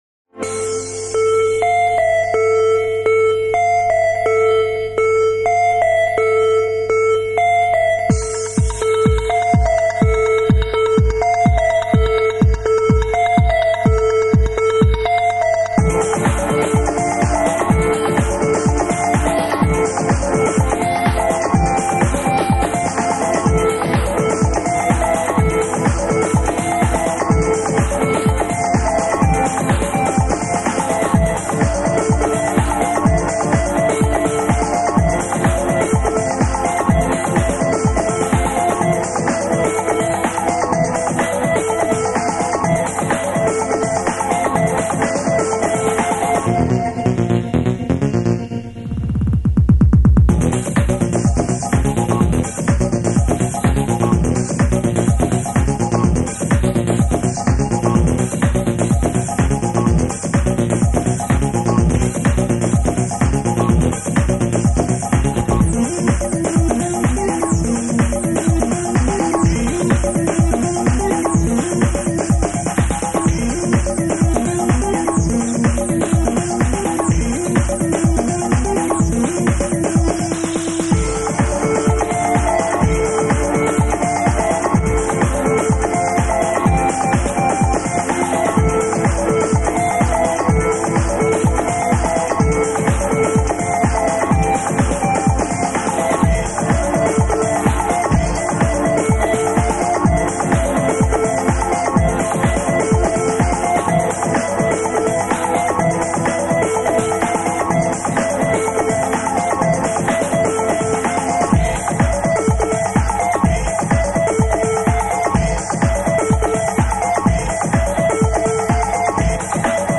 Autechre - IBC Pirate Radio Interview (1991).mp3